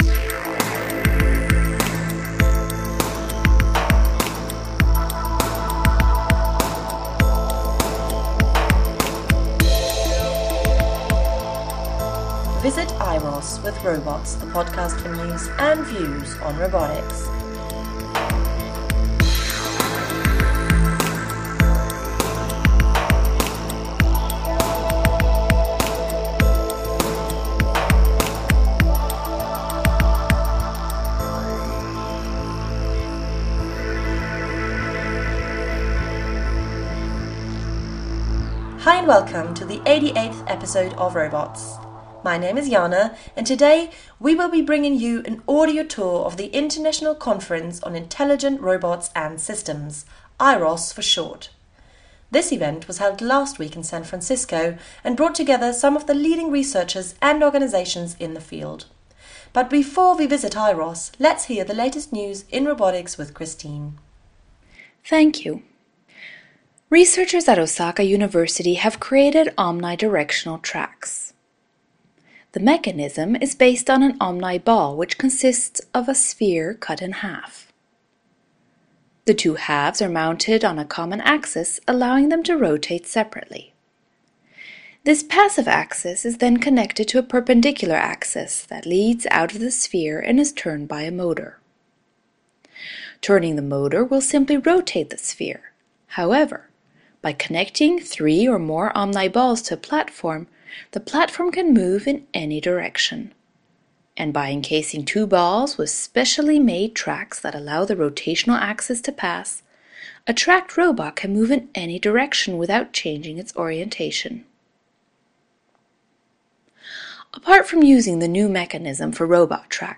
By bringing you around the permanent exhibit hall, we hope to give you a feel for what it was like to be there. We’ll be stopping at nearly every booth, talking to leaders in the field, asking about the robots we see, listening to robots present themselves, and even doing some demos along the way.